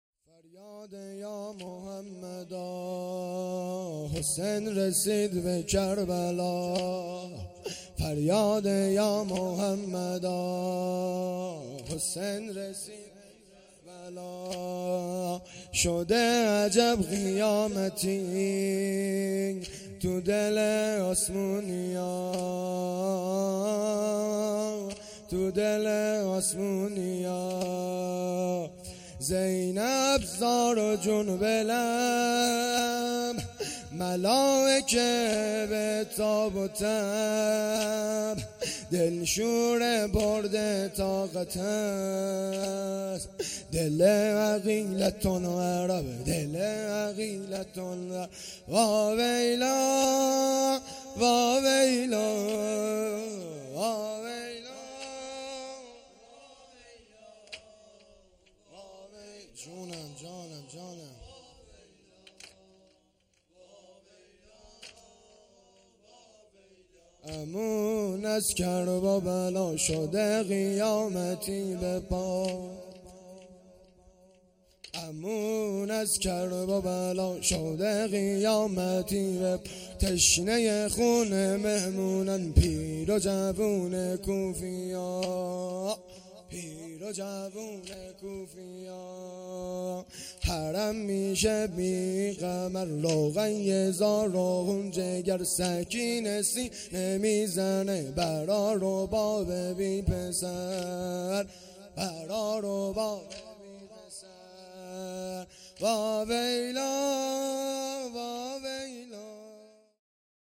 شب دوم محرم الحرام ۱۴۴۳